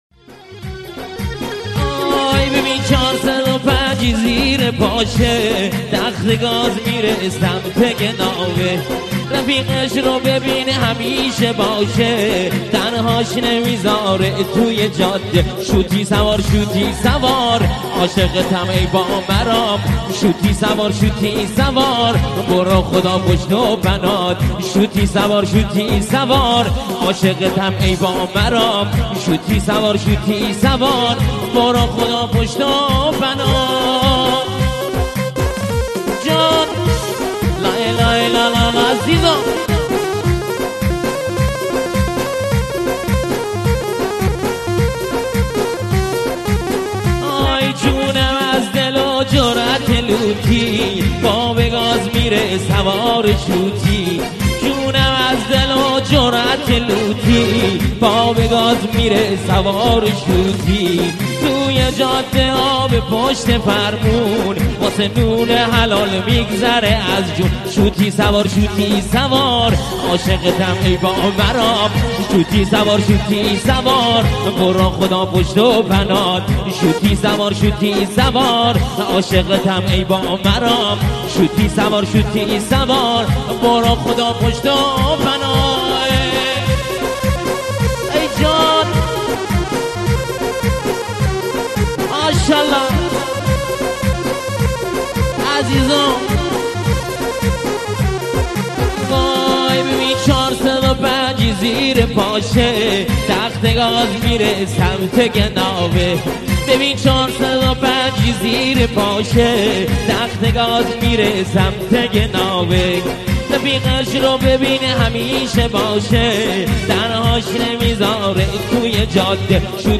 لاتی لری